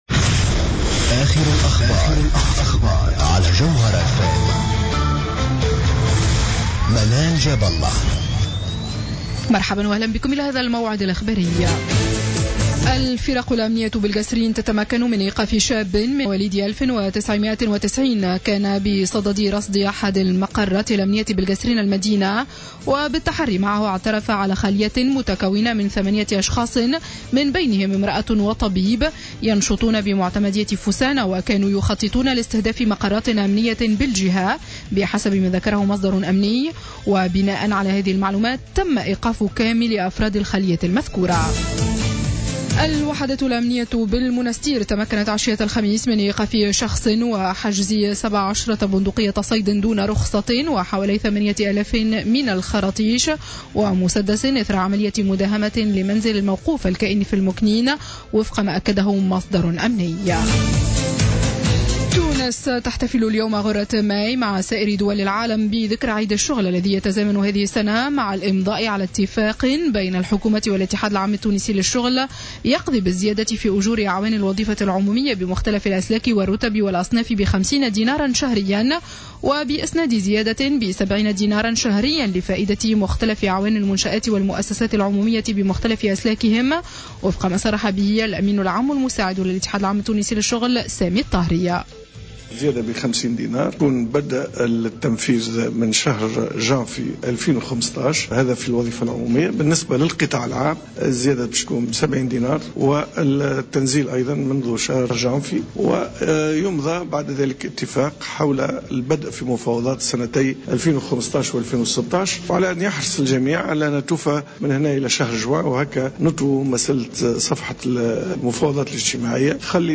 نشرة أخبار منتصف الليل ليوم الجمعة 01 ماي 2015